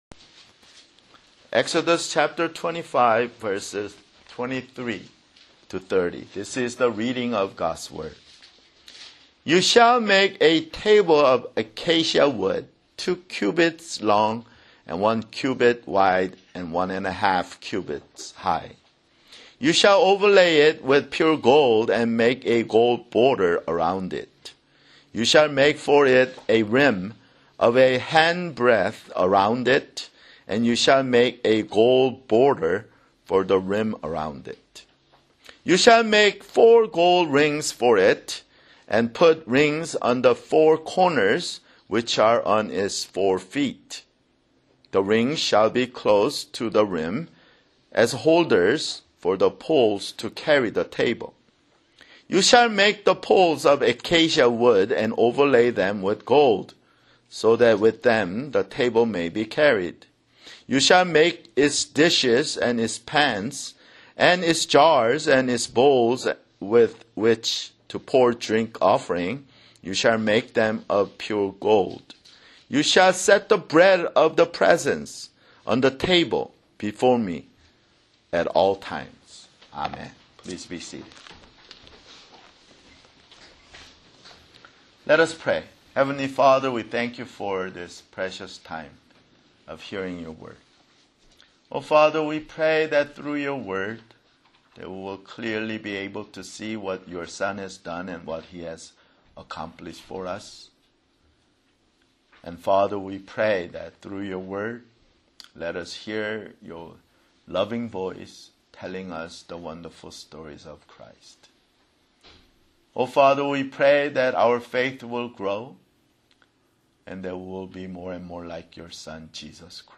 [Sermon] Exodus (75)